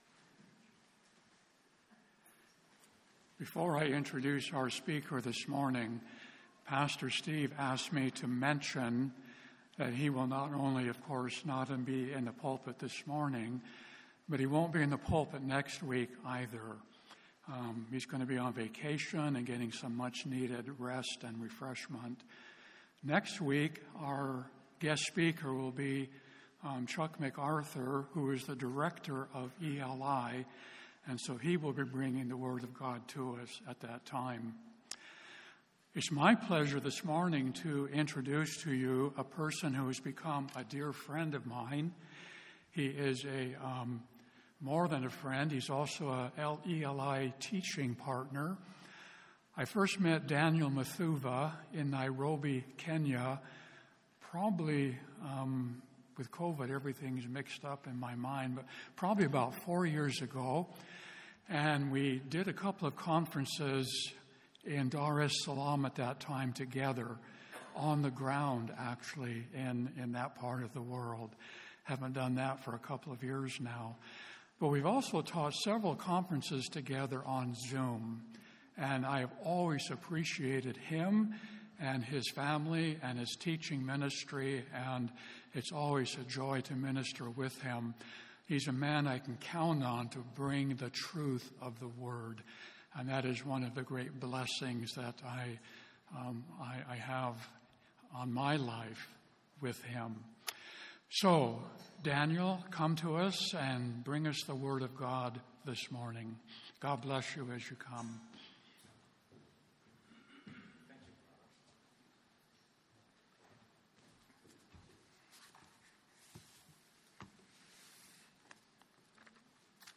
Sermons on Jonah 4 — Audio Sermons — Brick Lane Community Church